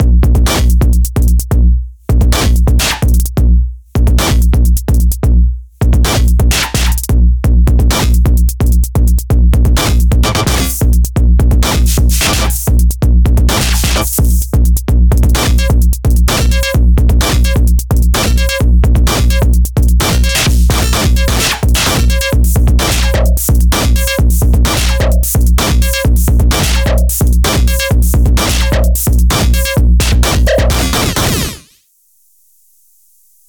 Microtonic-like drum synth in the grid, fun times